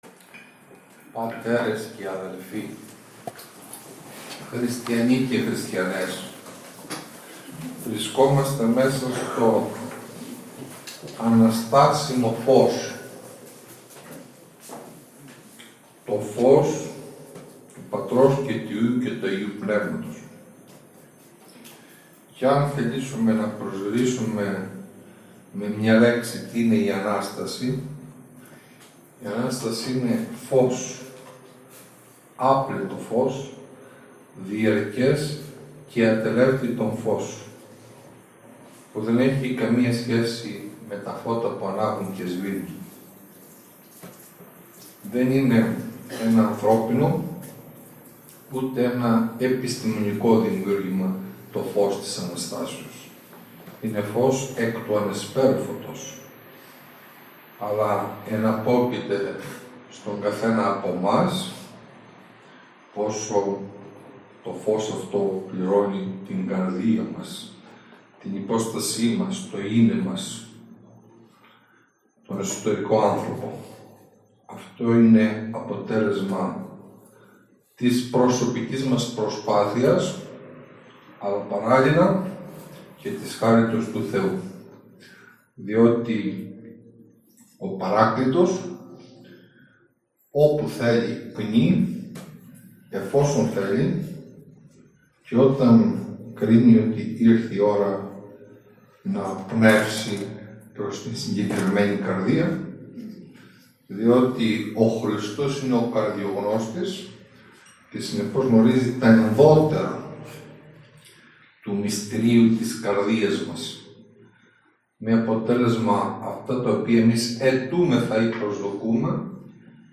Ο Μητροπολίτης Αρκαλοχωρίου στον Ι.Ν. Νέστορος στο Καυτατζόγλειο